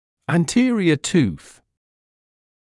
[æn’tɪərɪə tuːθ][эт’тиэриэ туːс]передний зуб